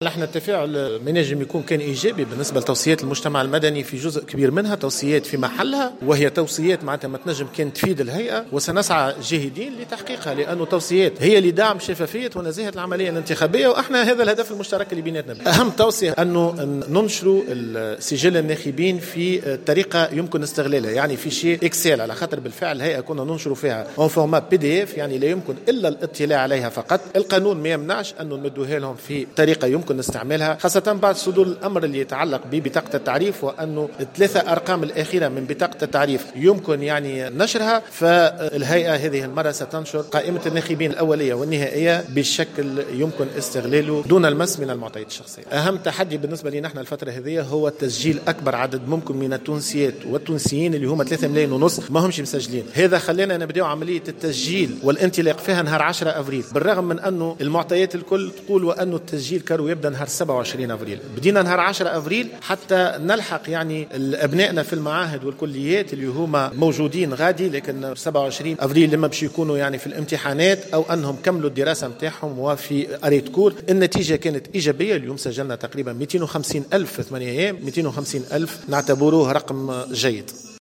أعلن رئيس الهيئة العليا المستقلة للانتخابات نبيل بفون، في تصريح لمراسل الجوهرة اف ام خلال يوم دراسي برلماني حول 'توصيات المجتمع المدني بخصوص مسار الانتخابات'، عن تسجيل 25 ألف ناخب جديد للانتخابات القادمة.